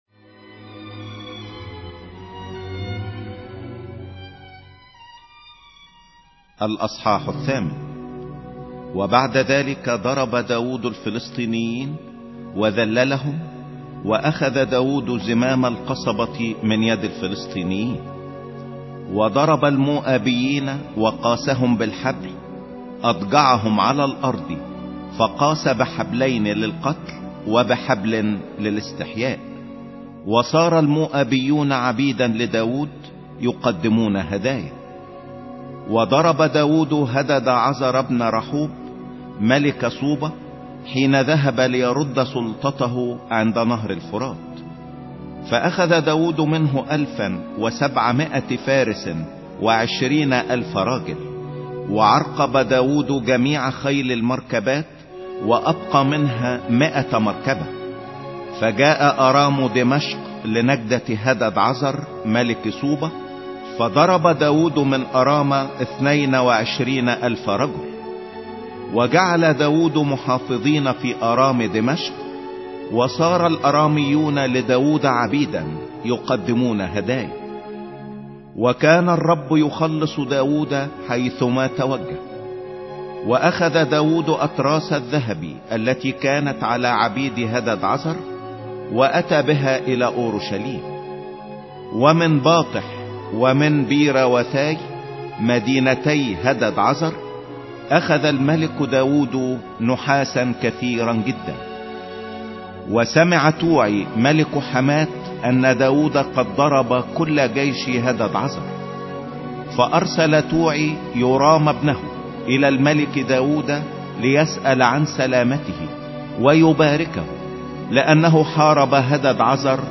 سفر صموئيل الثاني 08 مسموع